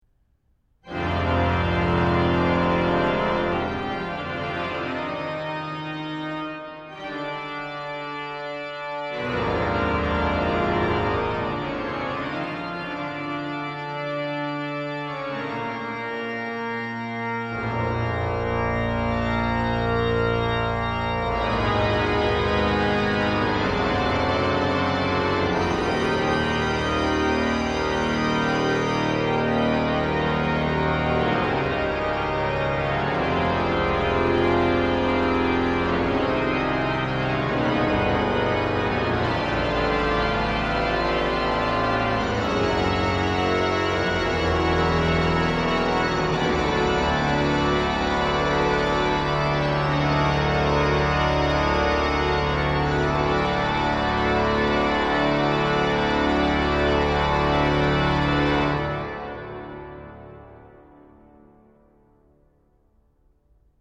buffet d'orgue de l' église SAINT-ETIENNE - CAEN ABBAYE aux HOMMES Cavaillé-Coll
tutti.mp3